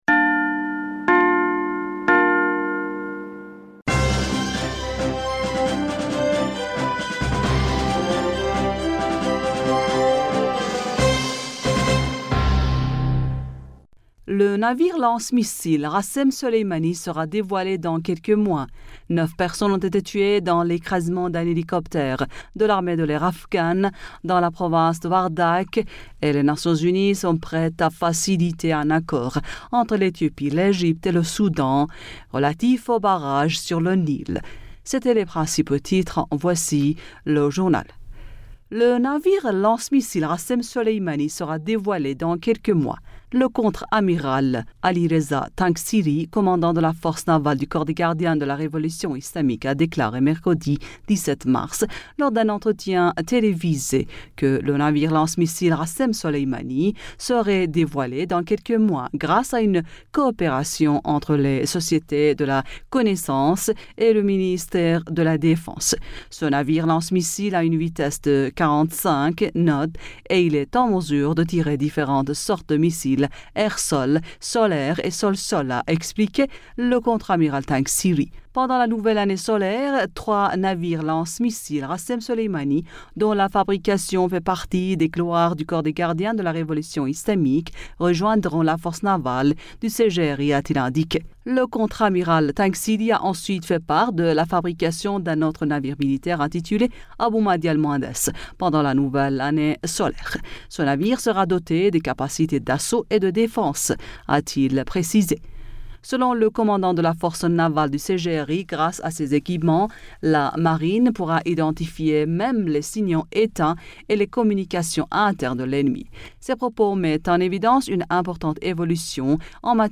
Bulletin d'informationd du 18 Mars 2021